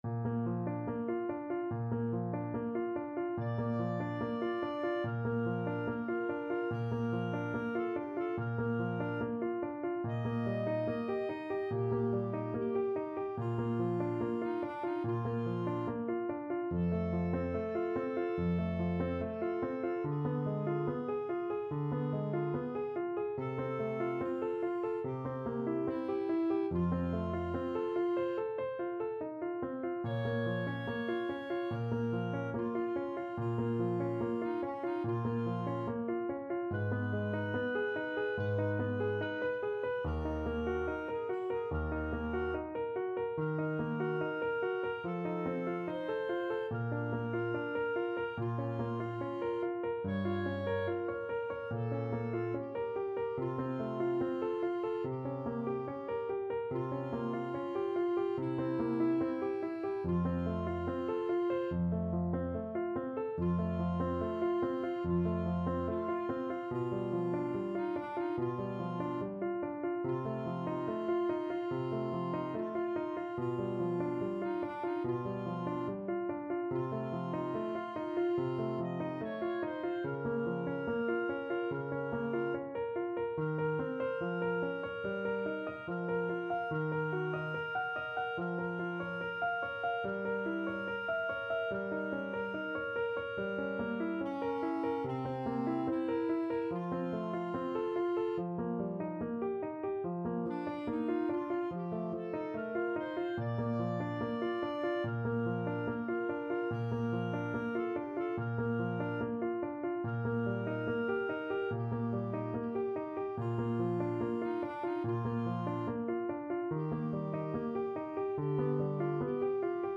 Andante =72
Classical (View more Classical Clarinet Duet Music)